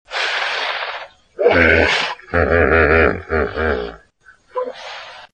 Звуки бегемота
На этой странице собраны натуральные записи, которые подойдут для творческих проектов, монтажа или просто любопытства.